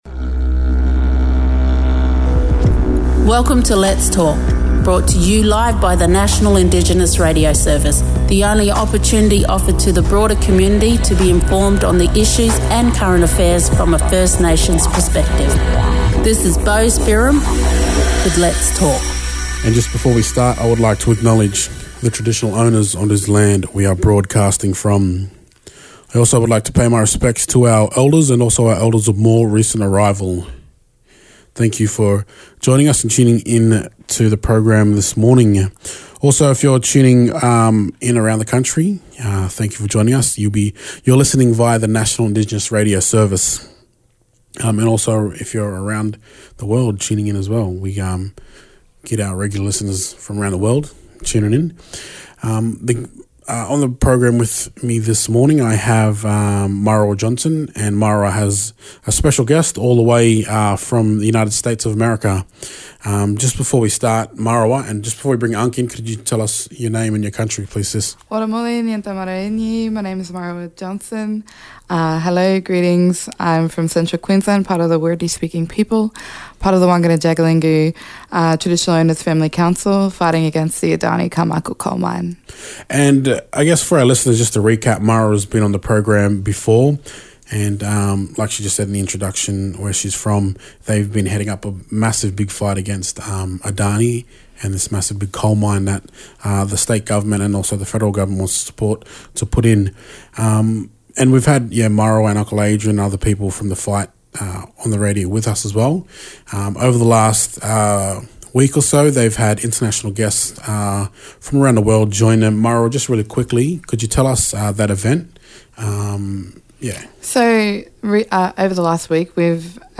First Nations men from America